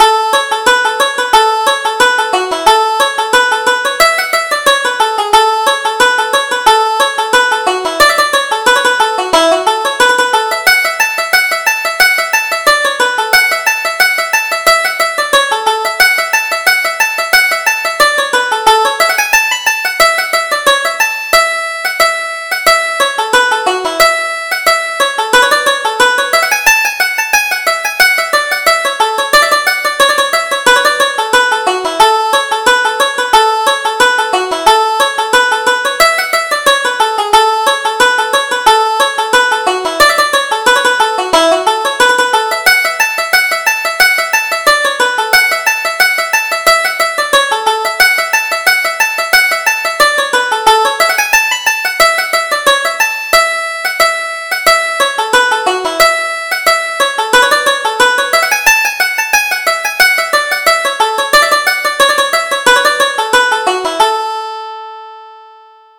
Reel: The Kerry Huntsman